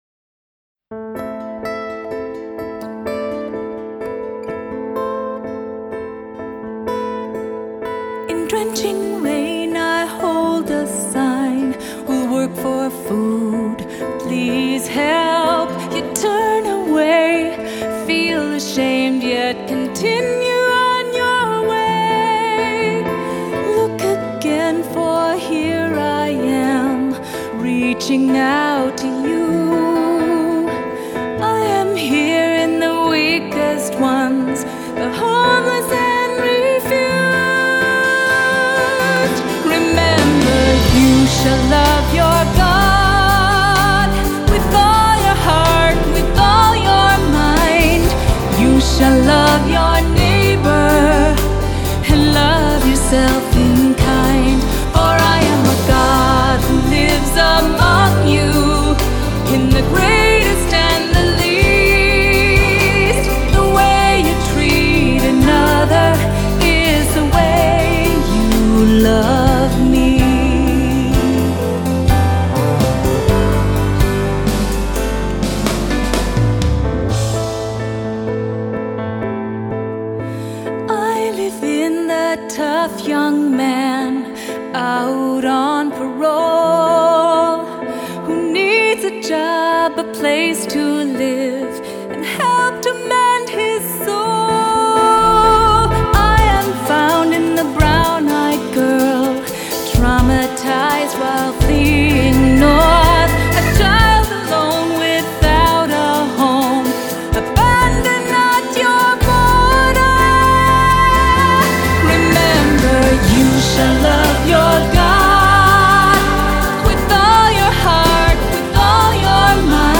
Accompaniment:      Keyboard
Music Category:      Christian
For cantor or soloist.